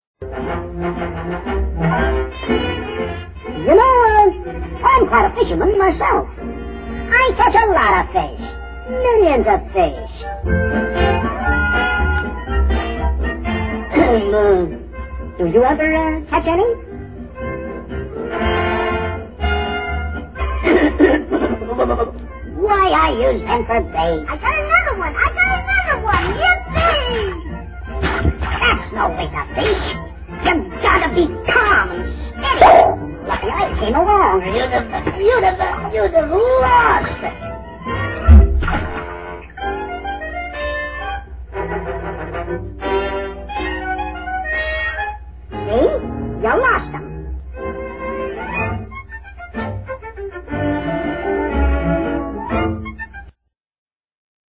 voice_howtofish_55s24kbs.wma